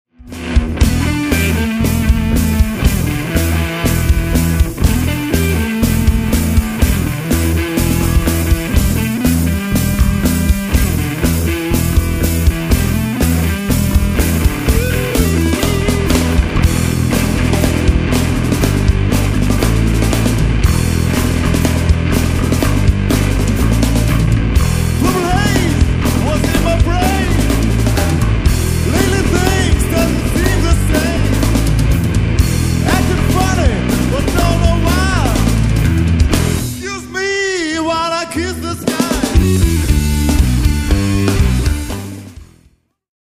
Recorded live at Brucknerhaus, Linz/Austria 8.11.2000.
guitars, lead vocals
bass, vocals
drums